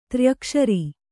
♪ tryakṣari